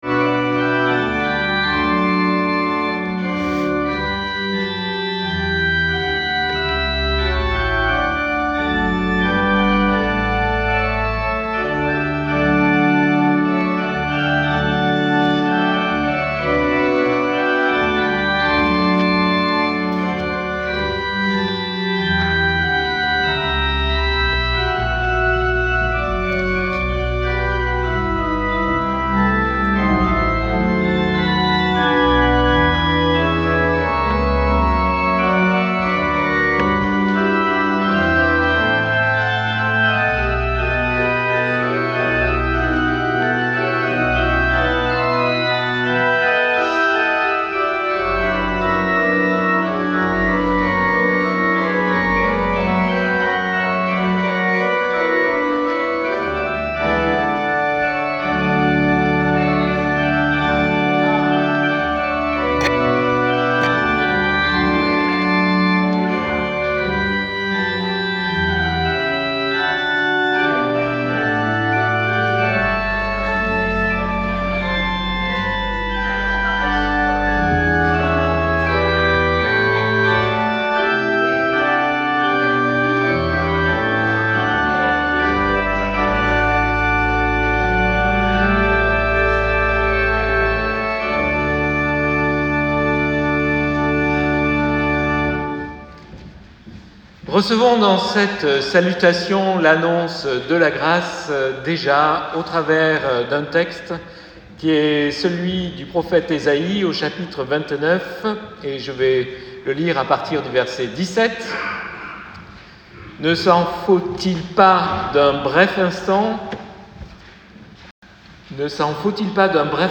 Audio: la prédication arrive à 23 minutes 30 ! ORGUE